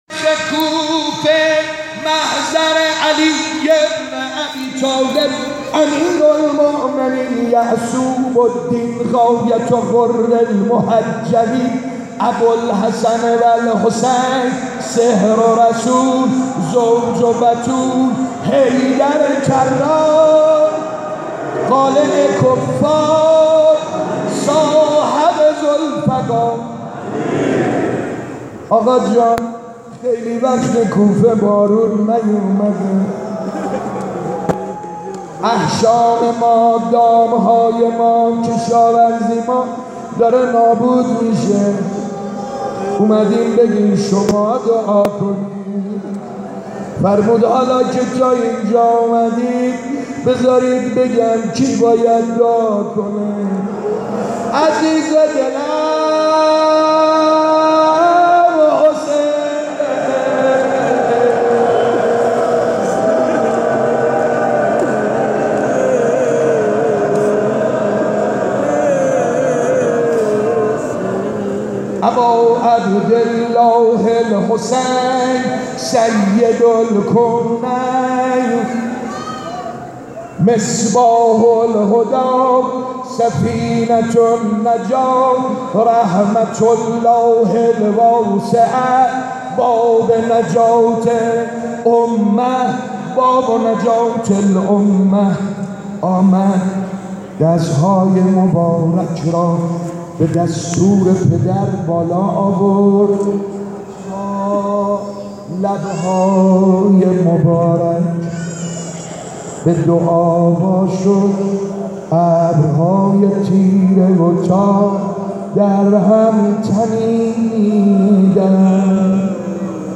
مراسم روضه آیت الله خاتمی